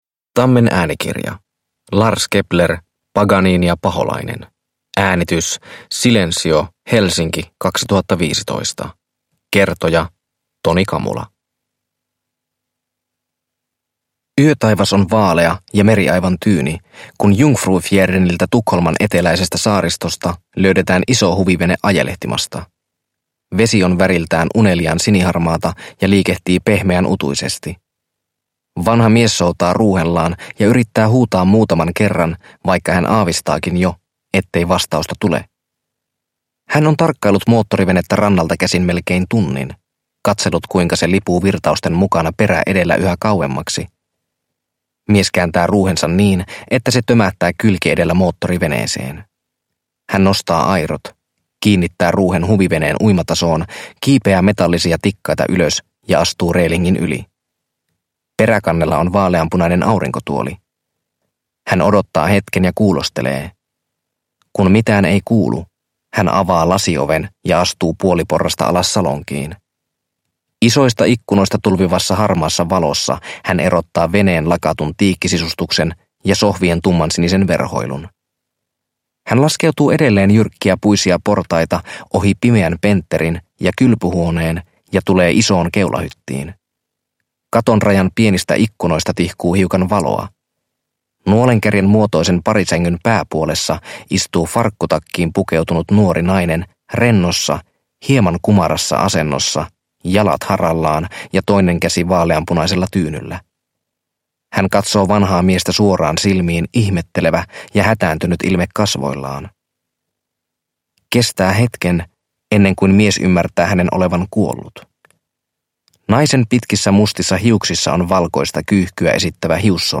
Paganini ja paholainen – Ljudbok – Laddas ner